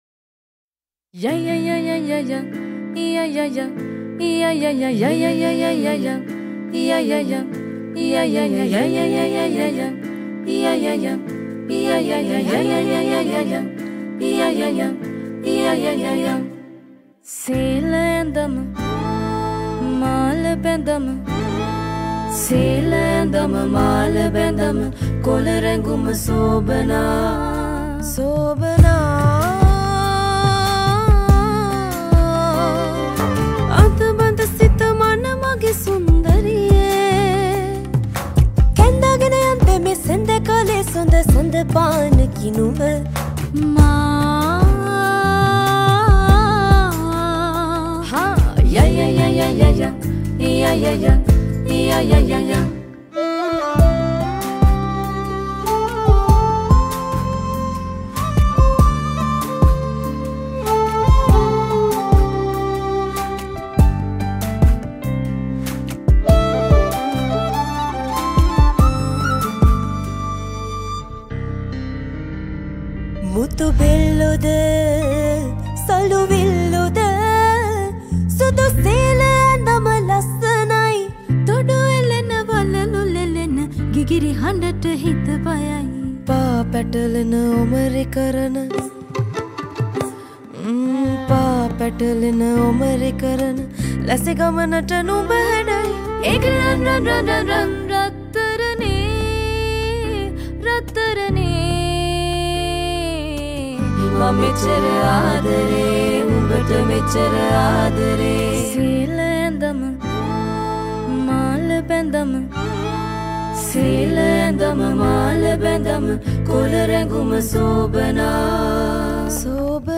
Releted Files Of Sinhala Mashup Songs